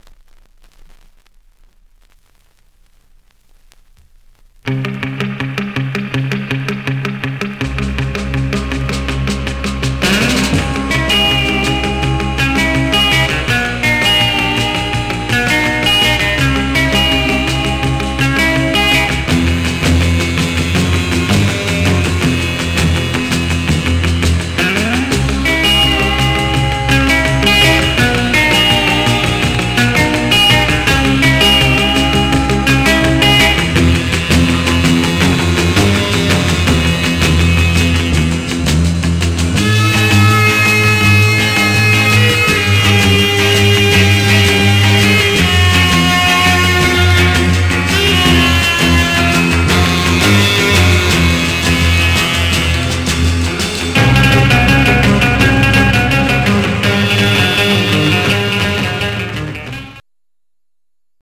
Stereo/mono Mono
R & R Instrumental